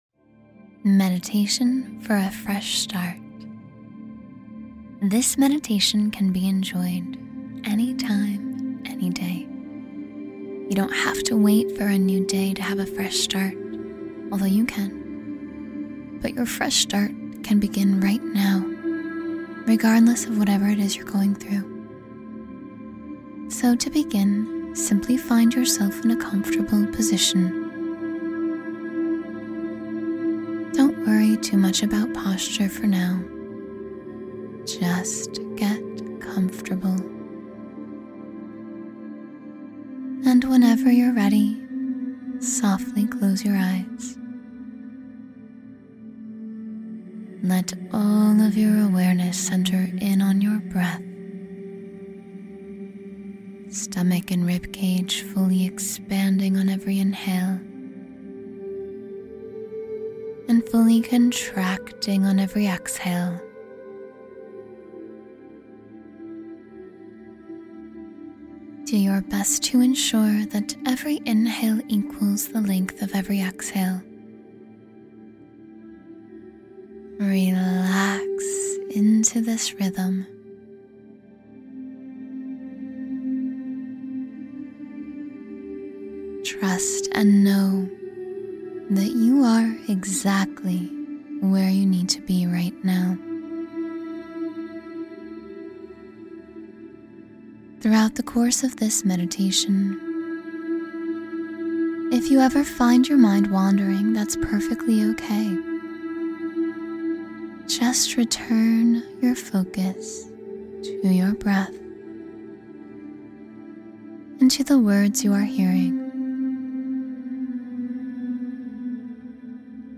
Wake Up and Shine — 10-Minute Morning Meditation for Inner Peace